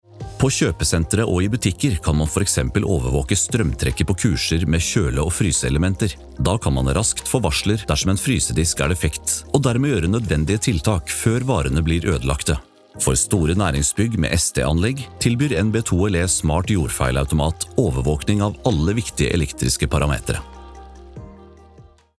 Commercieel, Diep, Natuurlijk, Vertrouwd, Vriendelijk
E-learning